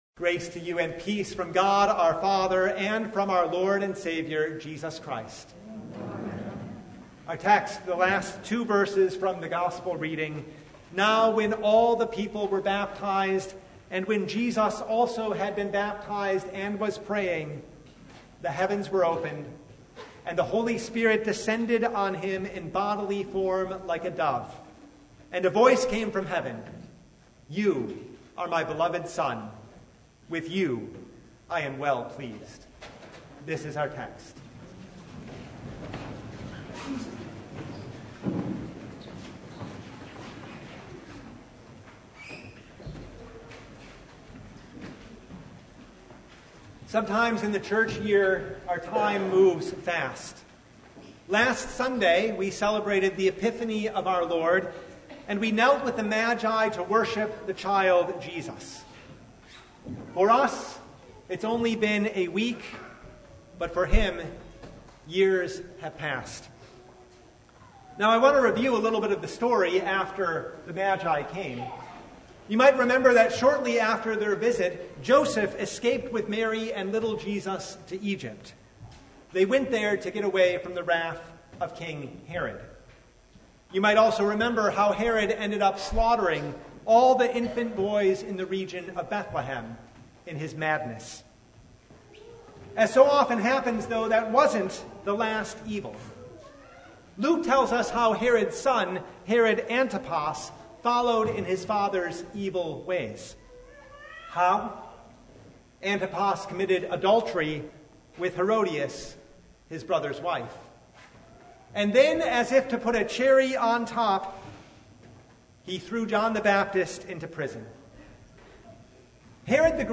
Luke 3:15–22 Service Type: The Baptism of Our Lord What does Jesus’ baptism have to do with ours?